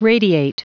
Prononciation du mot radiate en anglais (fichier audio)
Prononciation du mot : radiate